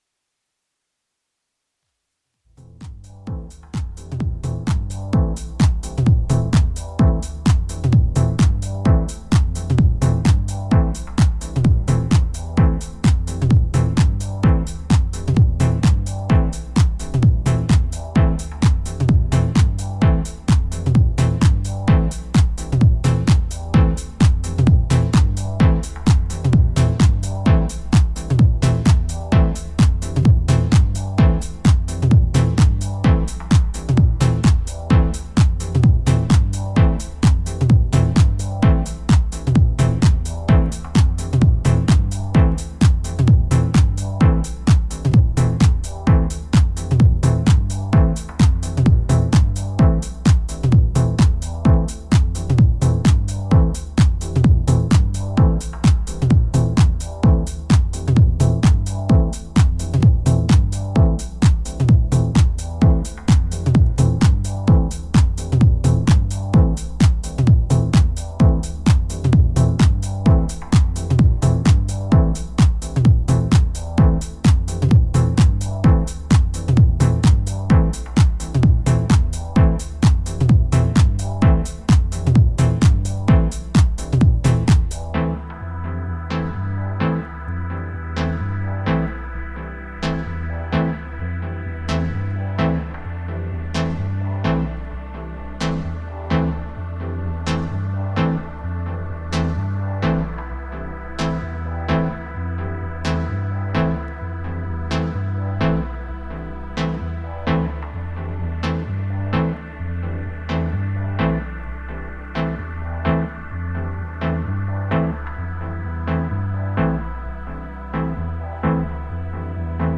ジャンル(スタイル) TECH HOUSE / MINIMAL HOUSE